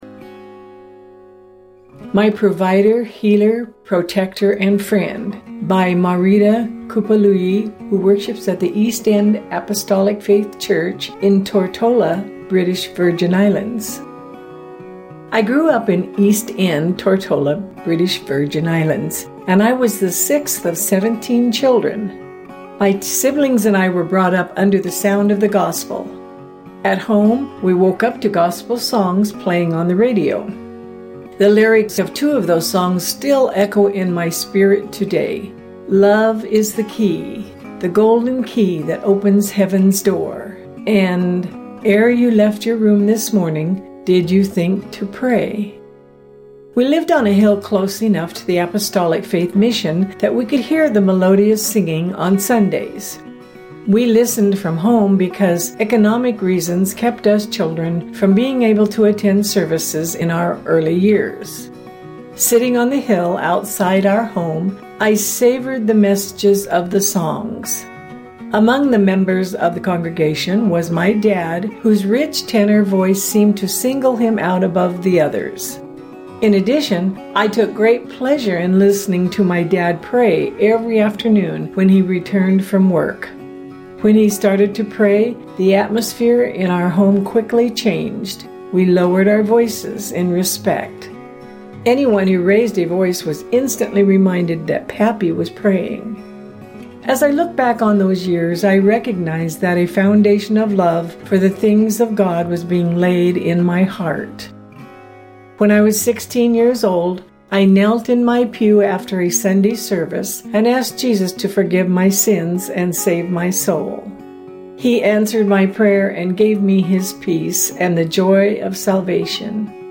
Witness